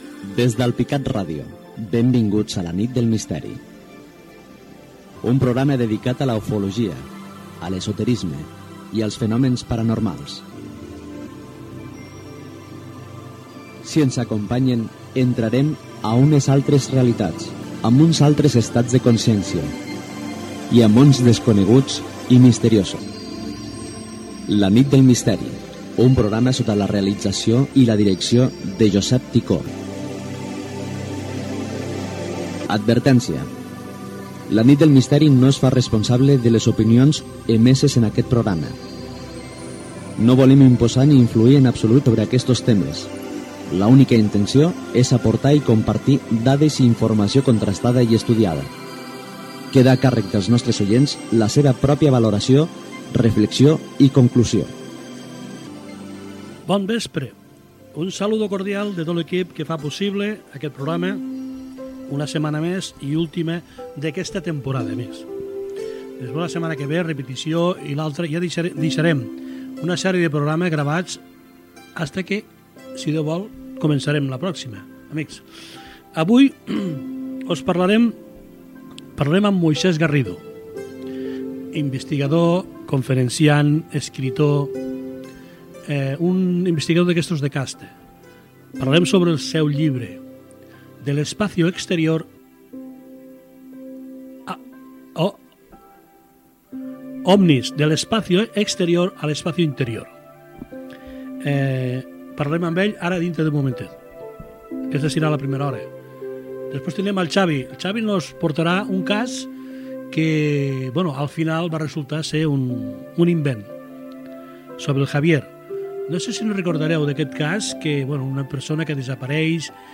Careta, presentació del darrer programa de la temporada 2016-2017. Sumari.
Divulgació
FM